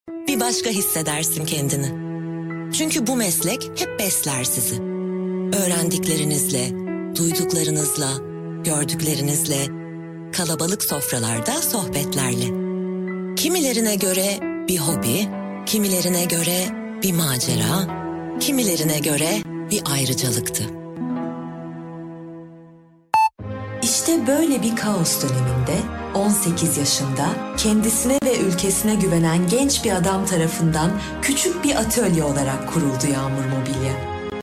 土耳其语样音试听下载